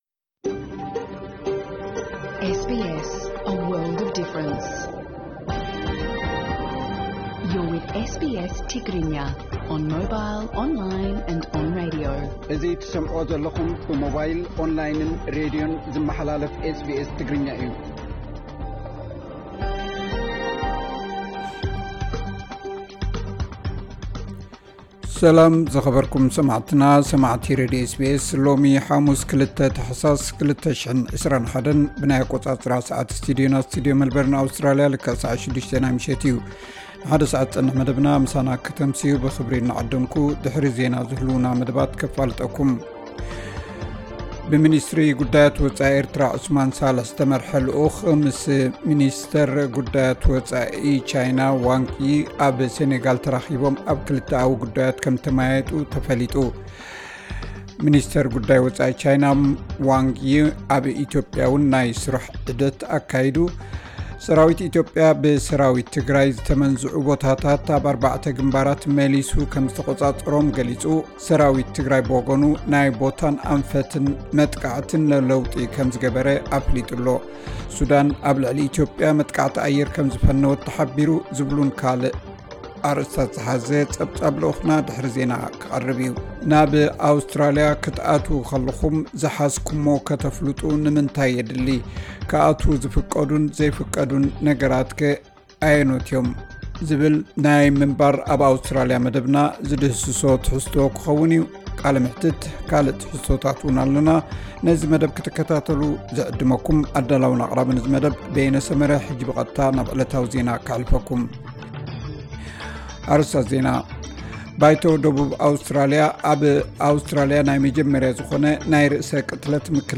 tigrinya_21221_news.mp3